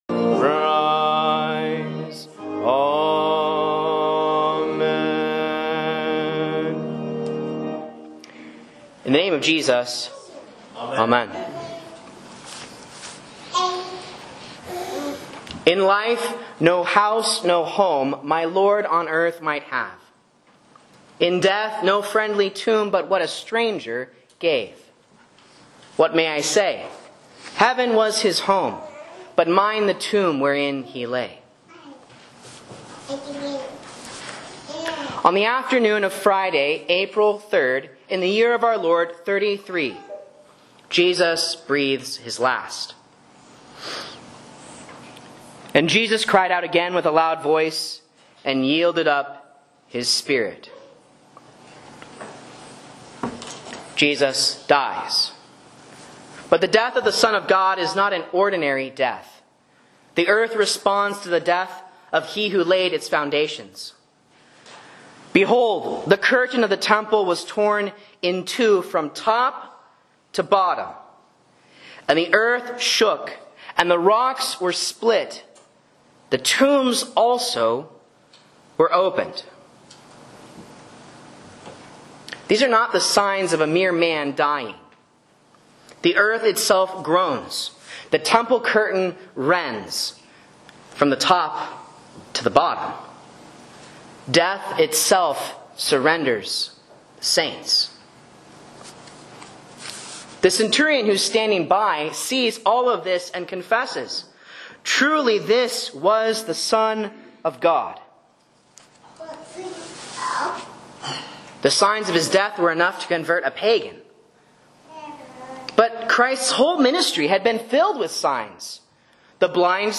A Sermon on Matthew 27:50-66 for Lent Midweek